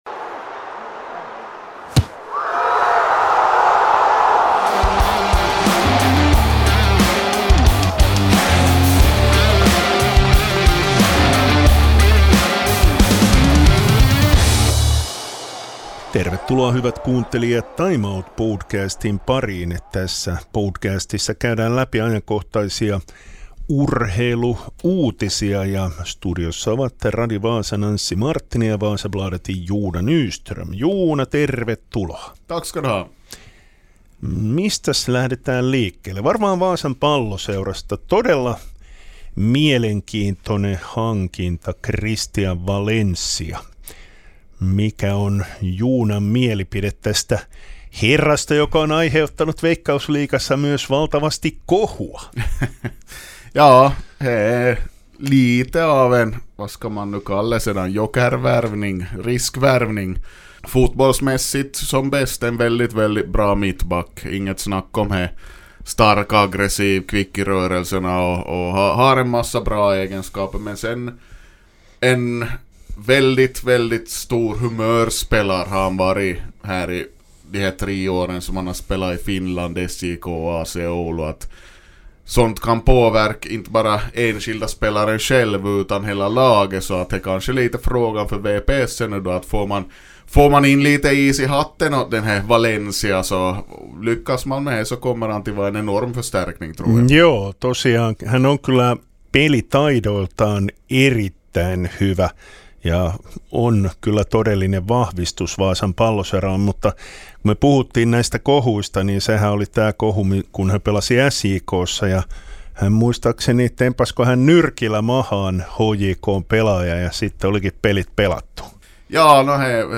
I studion